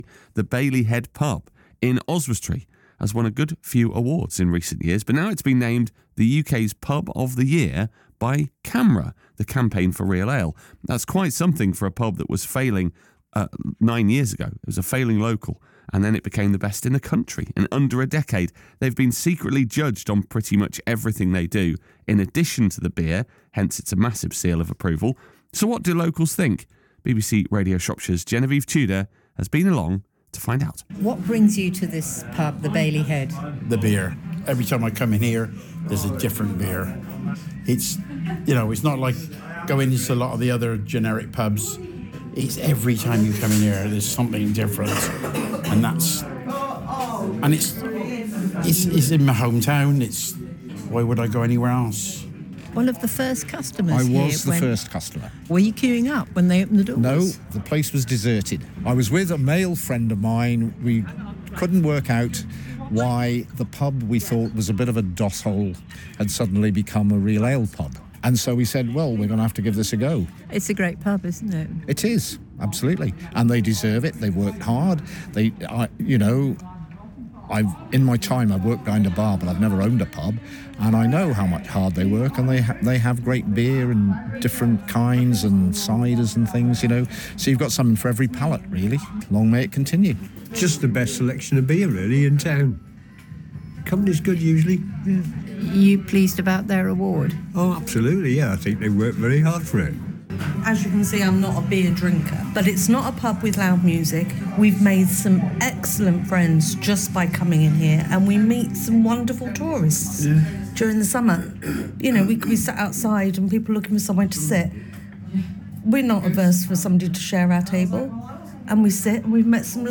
Vox Pops